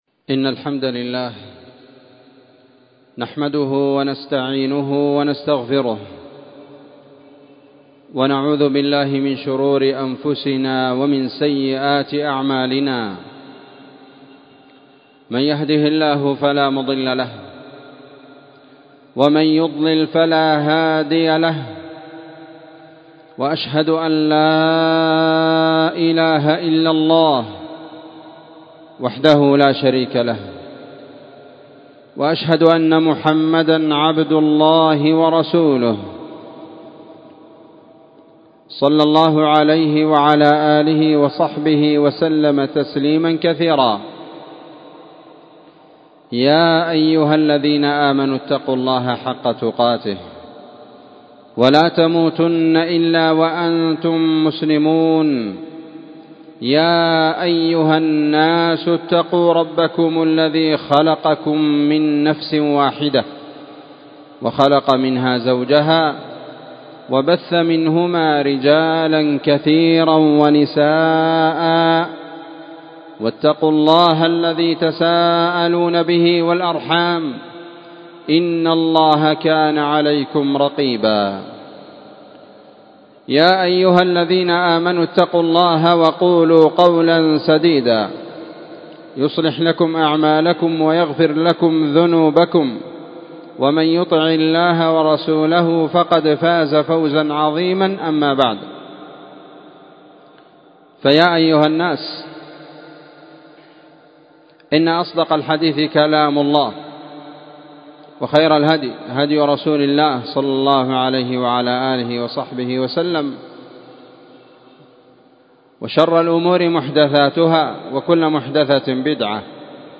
خطبة جمعة
مسجد المجاهد- النسيرية- تعز